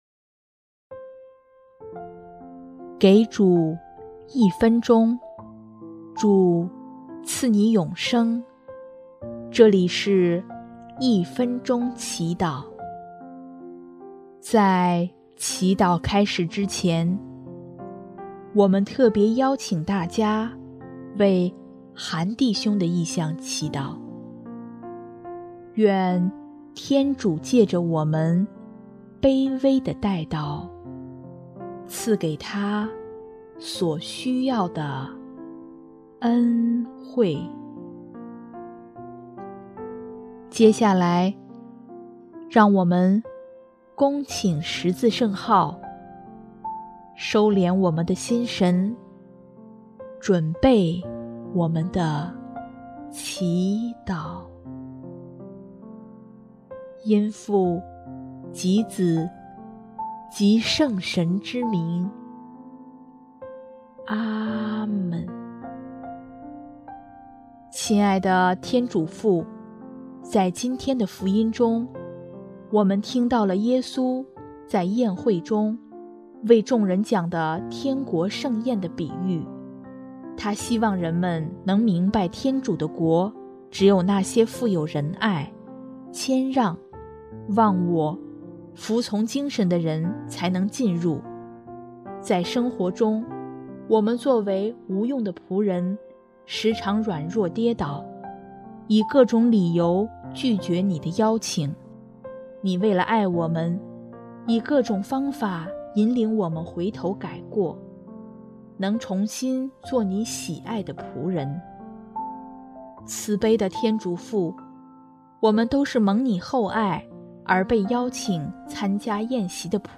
【一分钟祈祷】|11月7日 蒙上天恩宠，品天国喜宴
音乐： 第一届华语圣歌大赛参赛歌曲《以你的宝血》